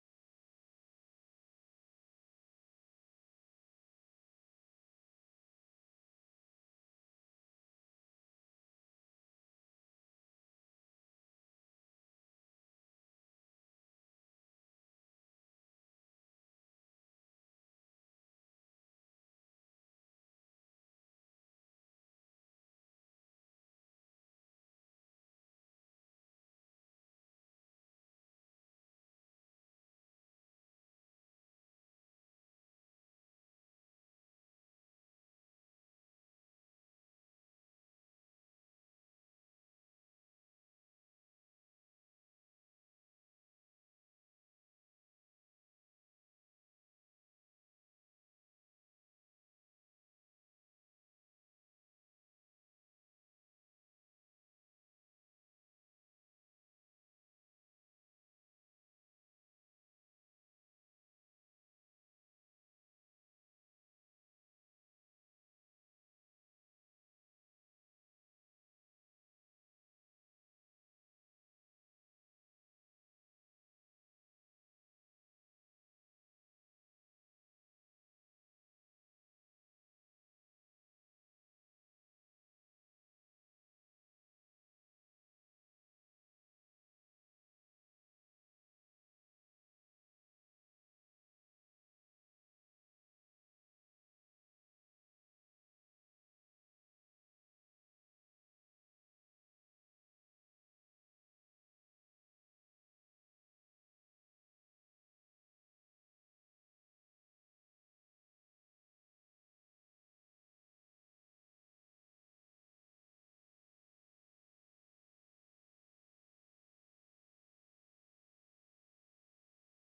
Live from New Ear Inc: New Ear :: presents (Audio)